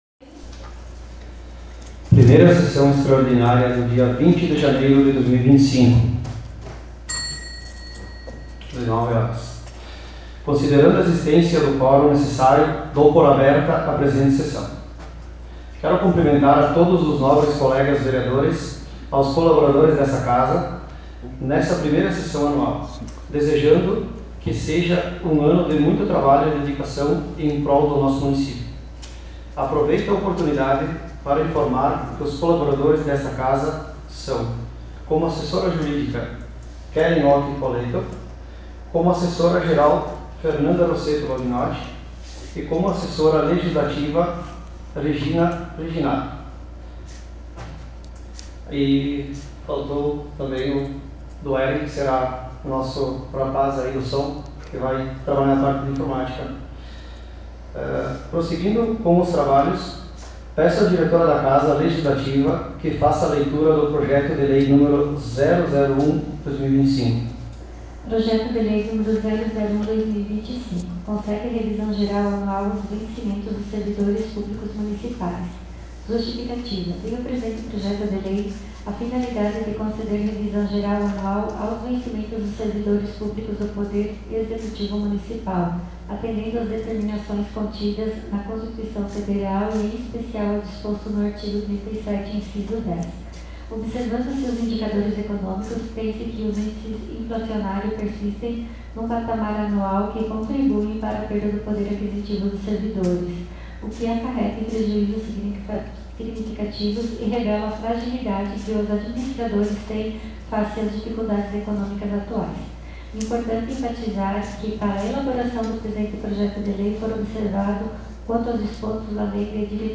Em anexo arquivo de gravação em áudio da Sessão Extraordinária realizada na Câmara de Vereadores de Vanini na data de 20/01/2025.